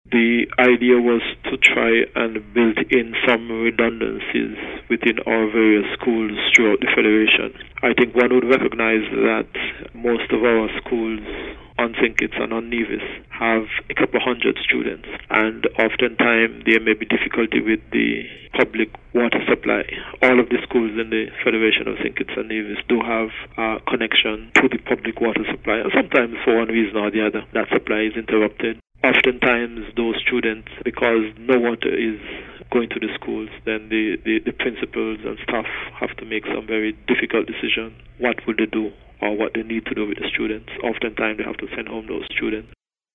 The VONNEWSLINE spoke with Minister Brand and gave us the rationale behind the project: